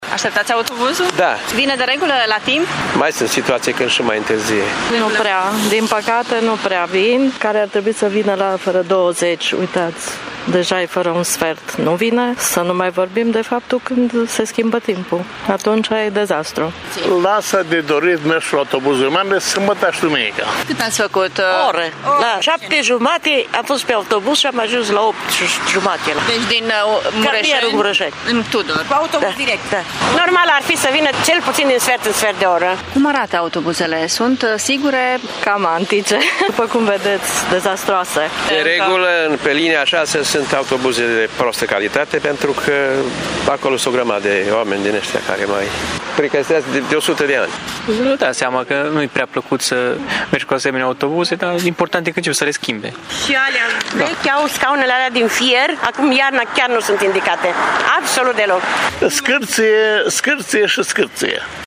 Călătorii din Tîrgu-Mureș sunt nemulțumiți că cea mai mare parte a autobuzelor care circulă în oraș sunt vechi, întârzie de multe ori, sunt nesigure și neconfortabile.